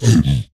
mob / zombiepig / zpigangry2.ogg
zpigangry2.ogg